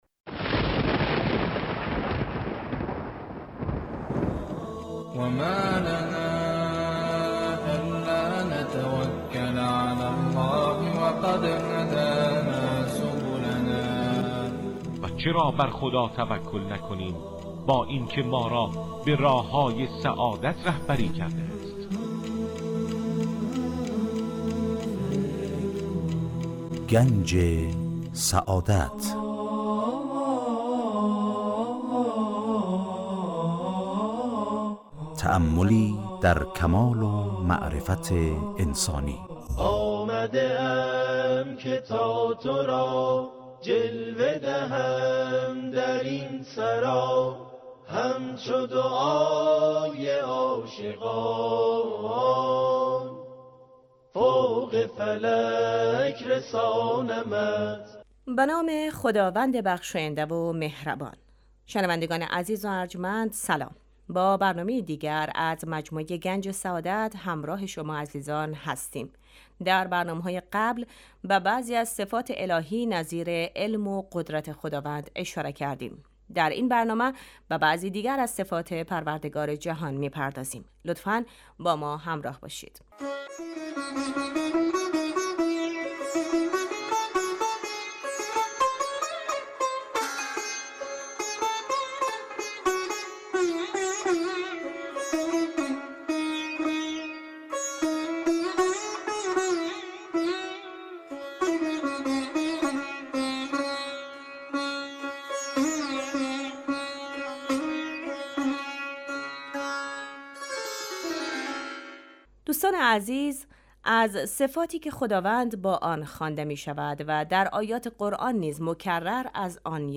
در این برنامه سعی می کنیم موضوعاتی همچون ؛ آفرینش ، یکتاپرستی و آثار و فواید آن، همچنین فلسفه و اهداف ظهور پیامبران را از منظر اسلام مورد بررسی قرار می دهیم. موضوعاتی نظیر عدل خداوند، معاد و امامت از دیگر مباحثی است که در این مجموعه به آنها پرداخته می شود این برنامه هر روز به جزء جمعه ها حوالی ساعت 12:35 از رادیودری پخش می شود.